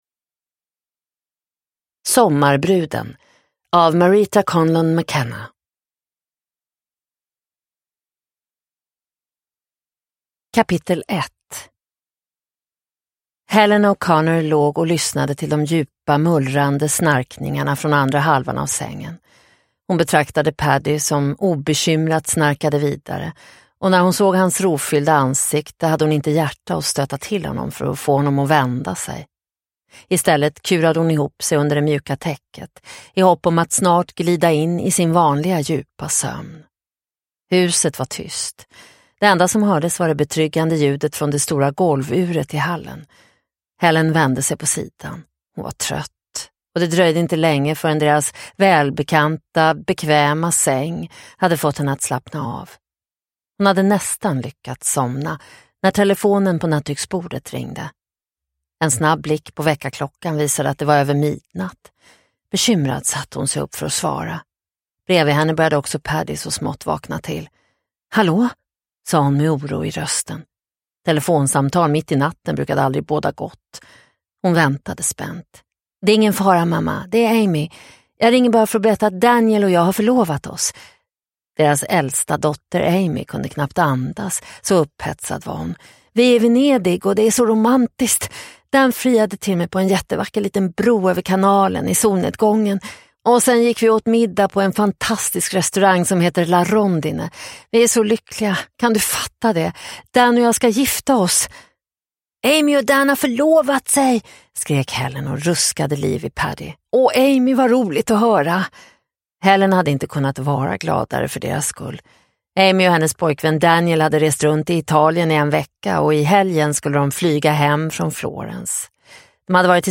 Sommarbruden – Ljudbok – Laddas ner